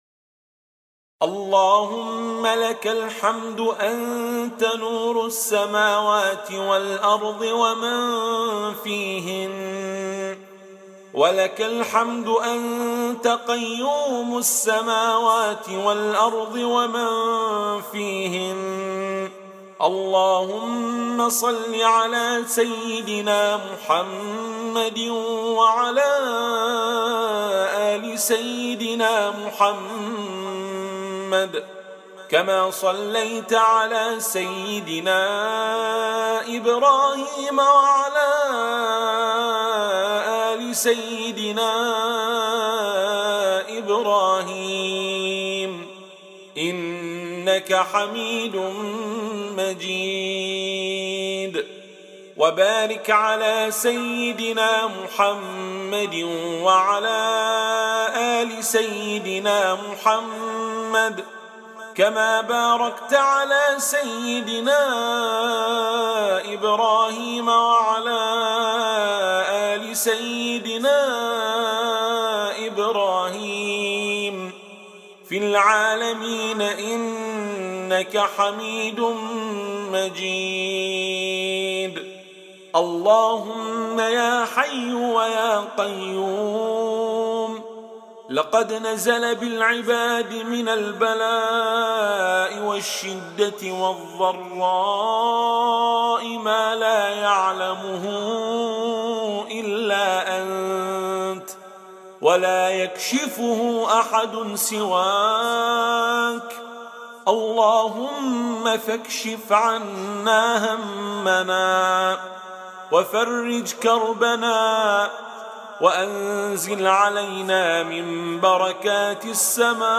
أدعية وأذكار
تسجيل لدعاء الاستسقاء المؤثر